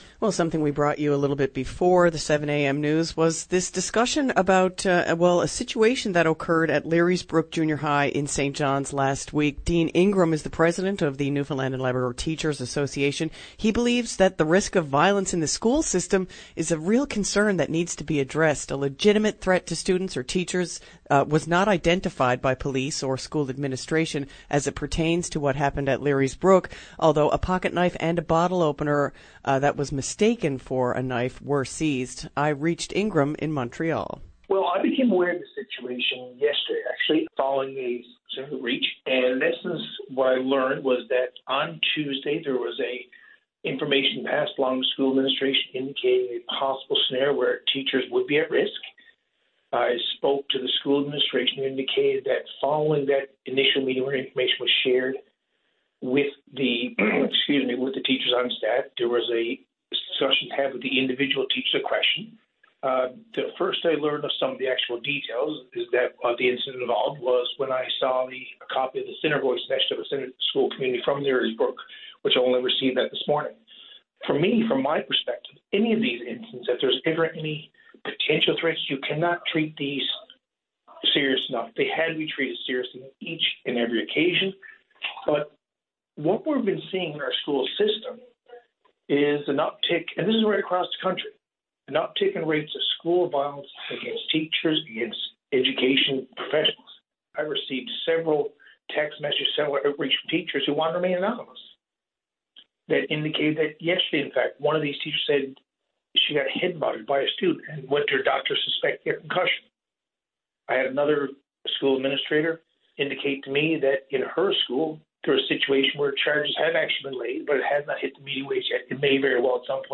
Media Interview - VOCM Morning Show Feb 10, 2020